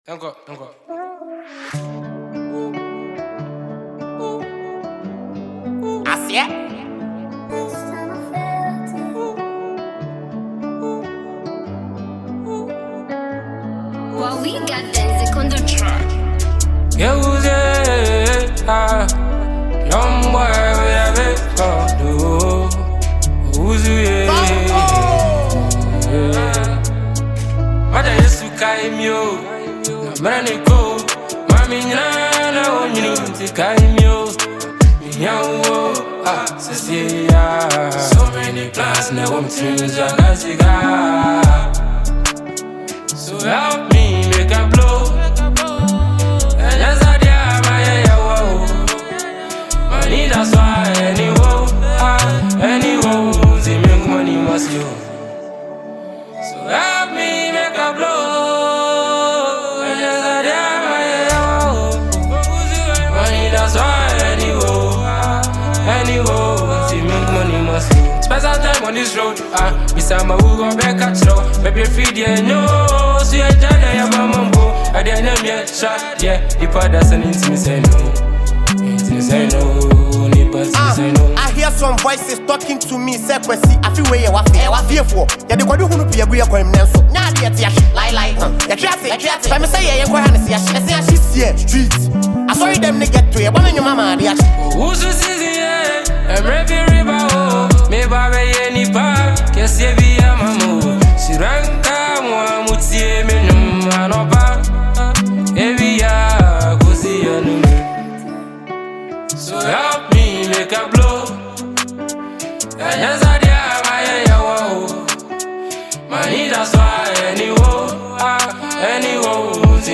a Ghanaian artist